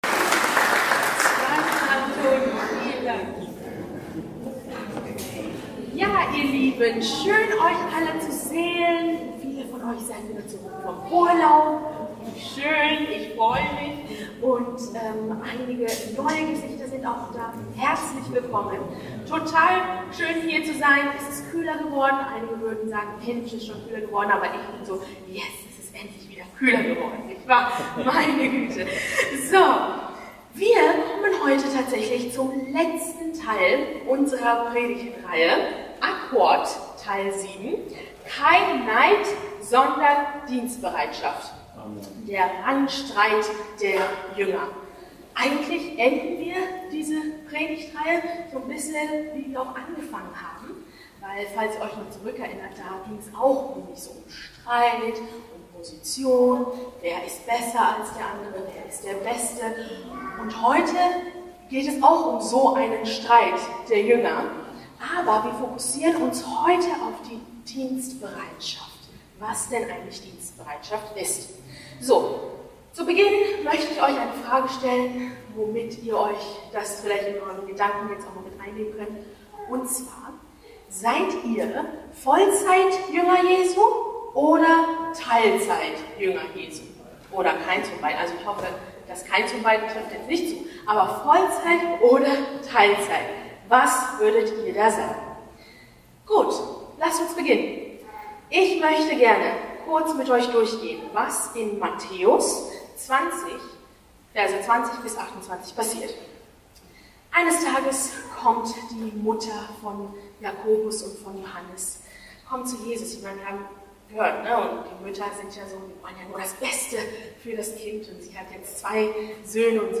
Herzlich willkommen zum heutigen Gottesdienst! Heute kommen wir zum letzten Teil unserer Predigtreihe Akkord – Das heutige Thema lautet- Kein Neid sondern Dienstbereitschaft.